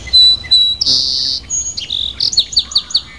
sparrow2.au